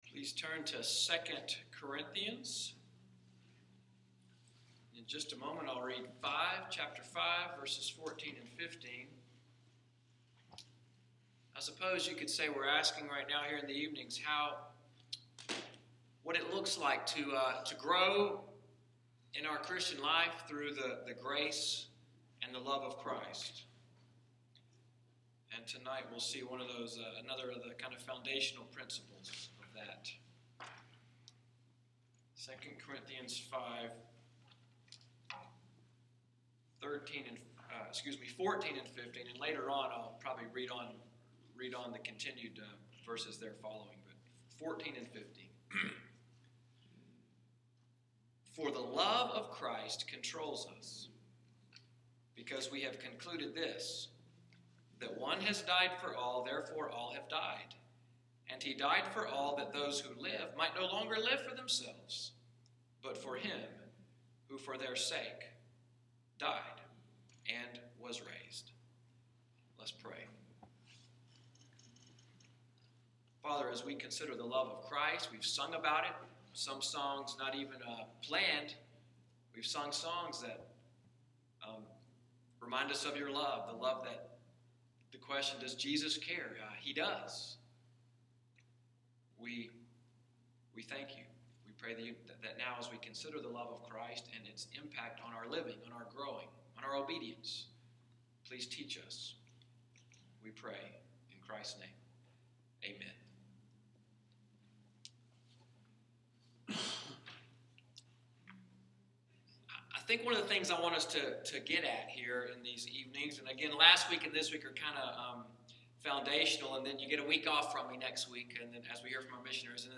New Covenant PCA, EVENING WORSHIP, Teaching / Discipleship sermon: Compelled by Christ’s Love, January 17, 2016